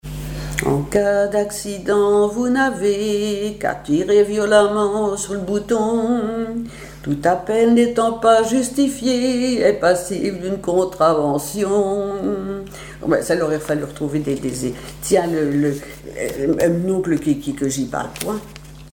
Genre strophique
Cantiques, chants paillards et chansons
Pièce musicale inédite